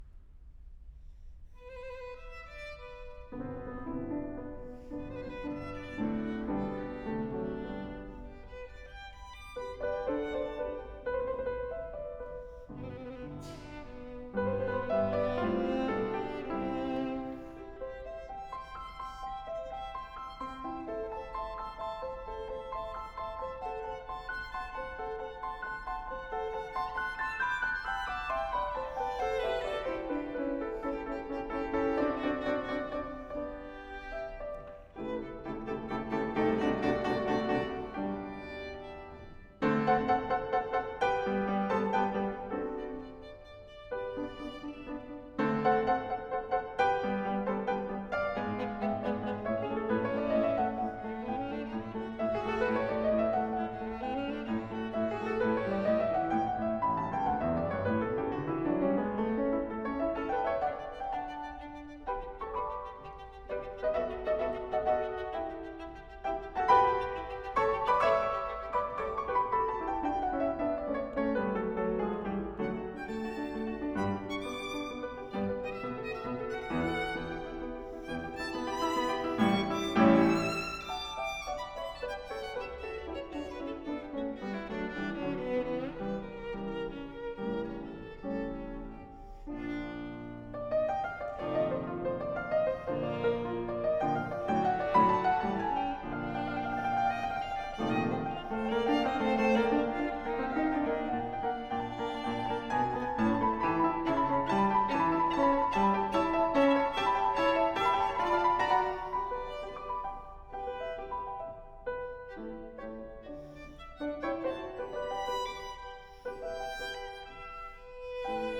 OK so here's the 3 separate mic pairs, raw, straight off the F8: